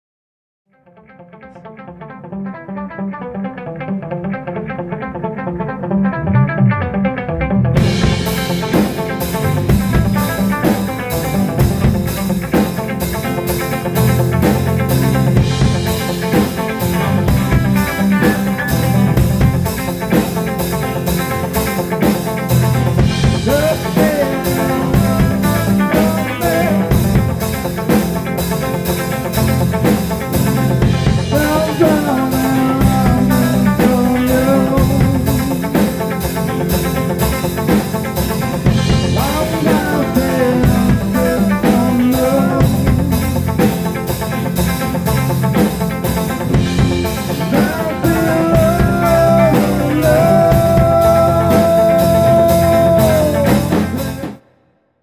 About five years later, during rehearsal, I taught my band how to play this nifty little fragment.
straw-man-improvisation.mp3